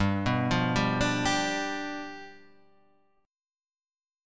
G 7th